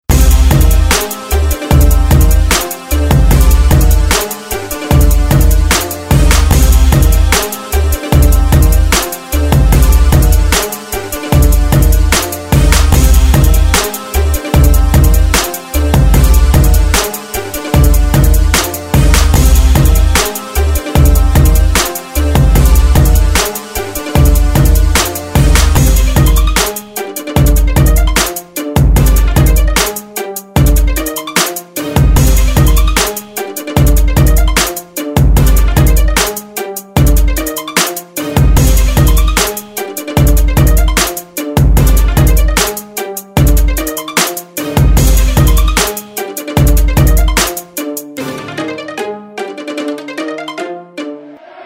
• Качество: 320, Stereo
без слов
Bass
instrumental hip-hop
Самодельная инструменталка песни